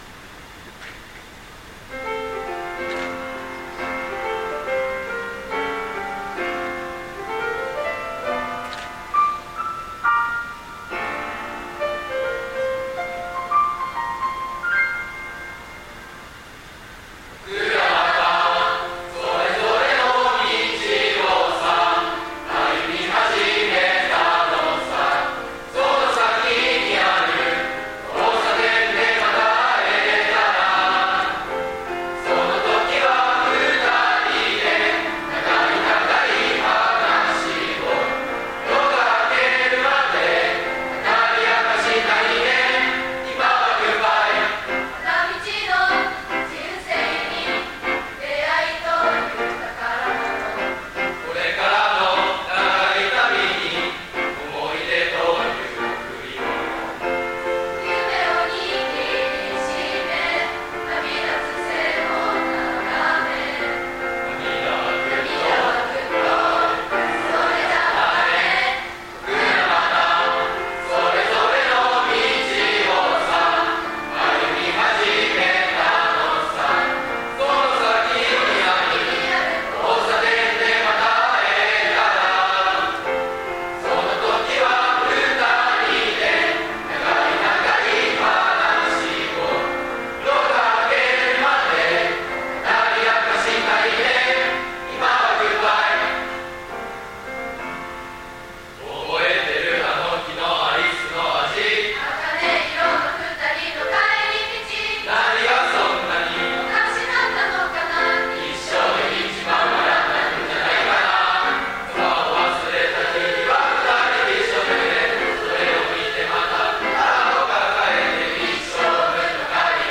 卒業証書授与式
「仰げば尊し」斉唱 歌詞の1番を卒業生、３番を在校生が歌いました。 校歌斉唱 唱歌・校歌の音声(MP3) 2026_03_01_唱歌・校歌.MP3 閉式のことば 卒業生の歌 卒業生が高校生活を振り返り、感謝の気持ちを込めて、三部合唱で『僕らまた』を歌いました。